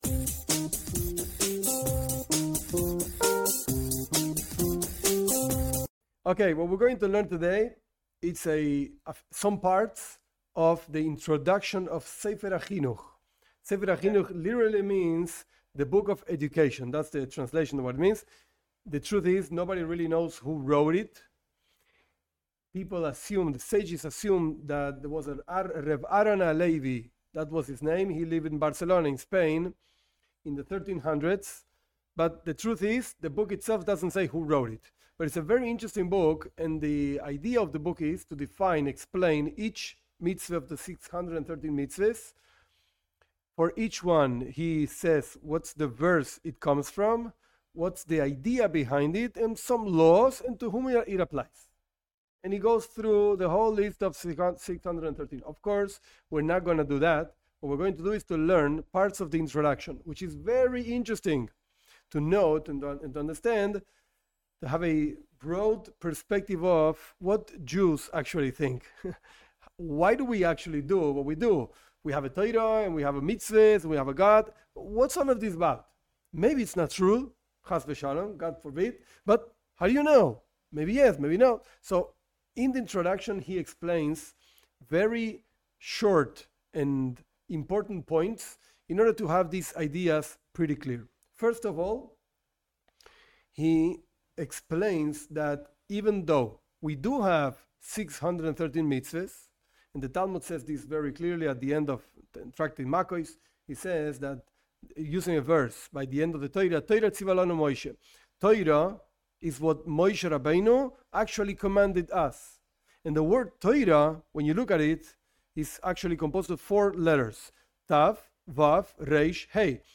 This class explains parts of the introduction of a classic Jewish book: The Sefer HaChinuch, the Book of Education. With this introduction we can understand the basic ideas of Judaism and the message for non Jews also.